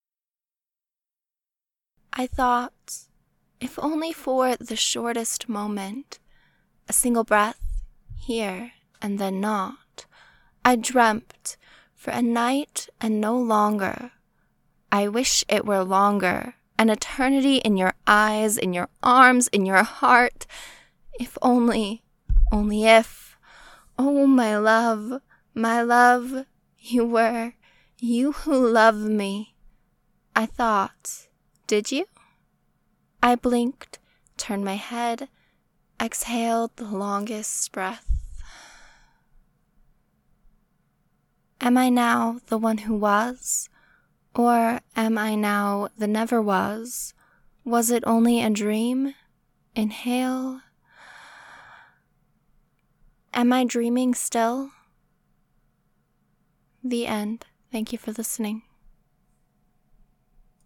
original poetry | Audiofic Archive